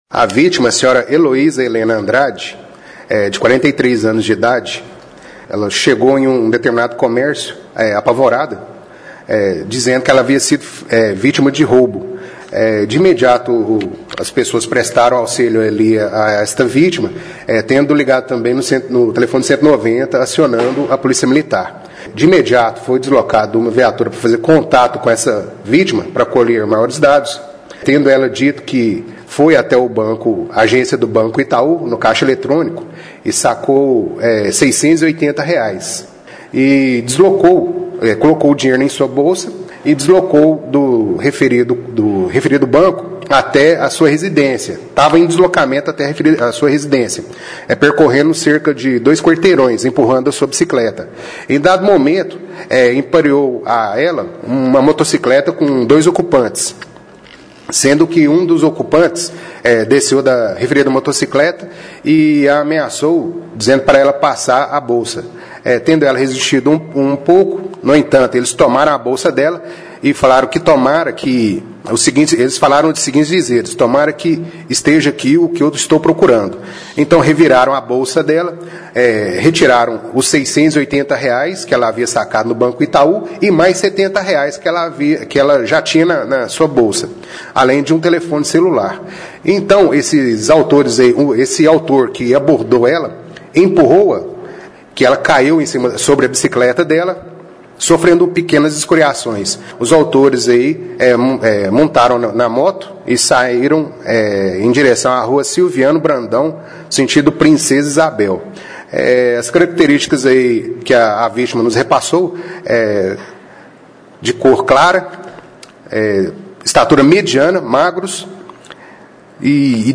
A Polícia Militar registrou um roubo a transeunte na tarde de ontem, por volta das três e meia, na Rua do Carmo, próximo ao cruzamento com a Rua Duque de Caxias, na região central. (Clique no player e ouça a entrevista).